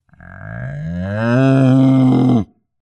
日本語だと「モーォ」と表記されることが多いですが、英語では「moo（ムー）」と表現されます。
牛の鳴き声-01 着信音